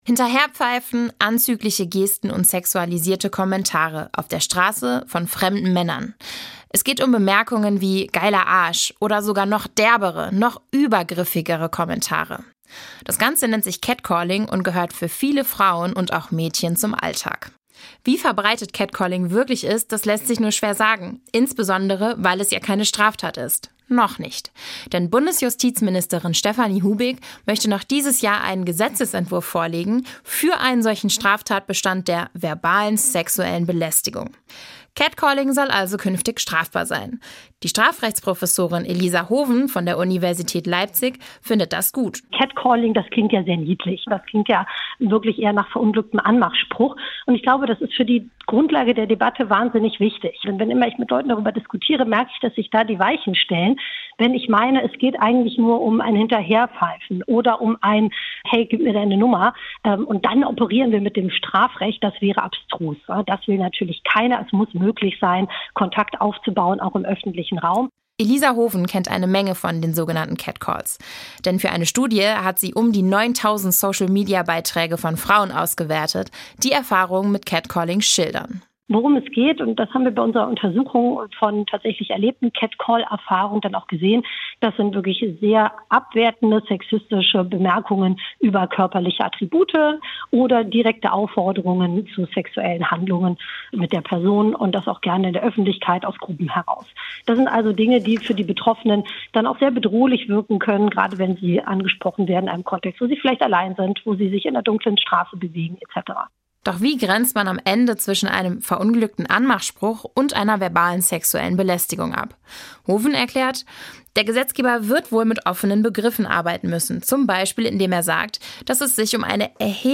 Aber wie grenzt man zwischen plumpen Komplimenten und strafwürdiger verbalen sexueller Belästigung ab? Und könnte man so ein Verbot überhaupt durchsetzen? Über diese Fragen spricht der ARD Radioreport Recht mit der Strafrechtsprofessorin